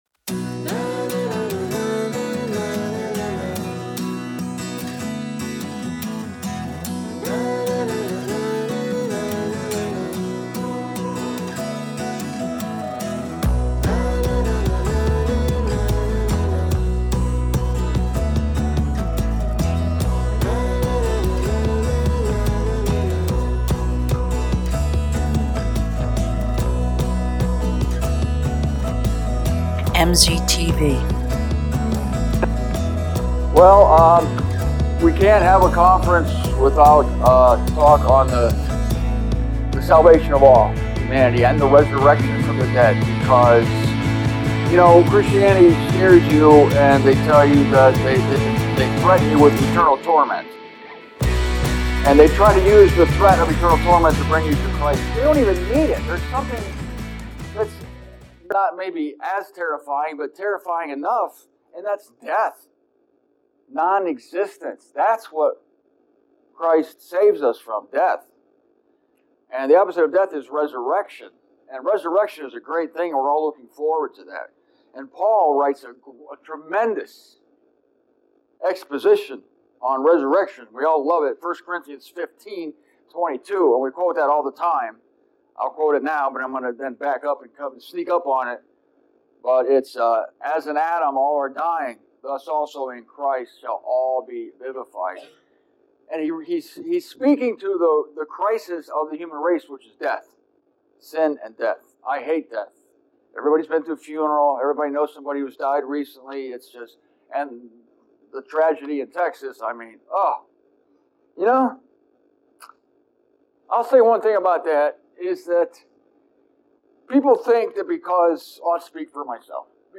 I apologize for the sound quality of this video. Apparently, the lapel mic I brought to the conference sucked.
There was a constant crackle throughout this video, so I had to de-noise it and add a filter.